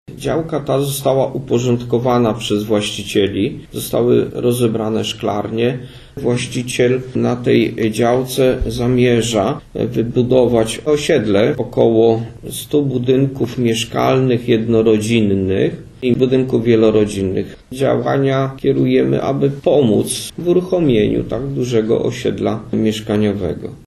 Gmina nisko jest na etapie wydawania decyzji środowiskowych dotyczących podziału istniejącej działki na działki budowlane. Mówi burmistrz Niska Waldemar Ślusarczyk: